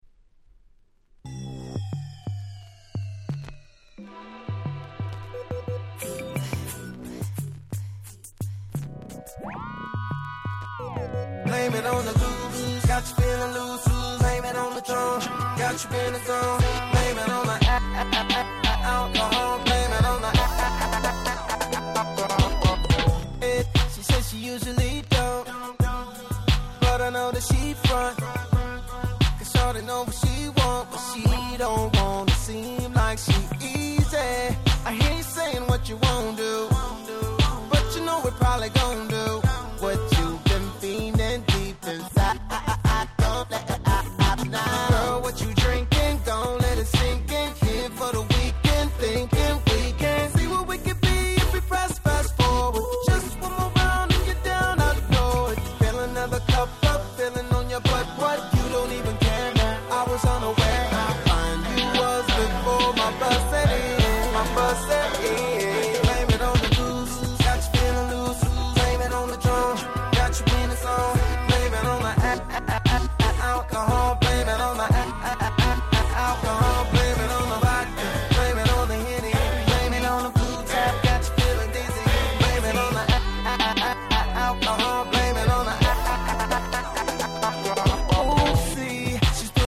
09' Super Hit R&B !!